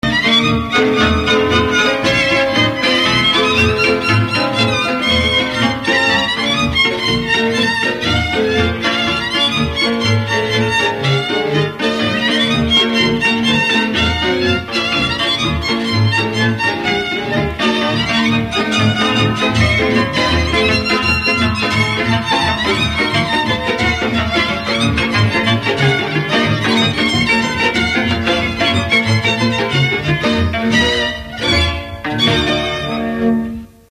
Felföld - Gömör és Kishont vm. - Alsókálosa
hegedű
brácsa
cimbalom
bőgő
Stílus: 4. Sirató stílusú dallamok
Kadencia: 6 (5) 2 1